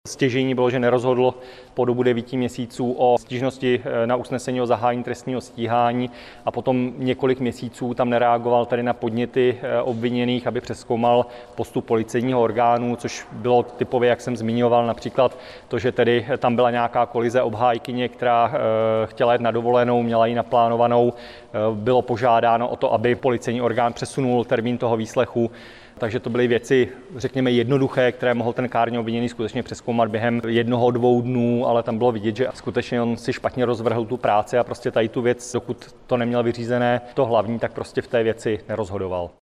Předseda senátu Petr Mikeš o kárném provinění Jaroslava Šarocha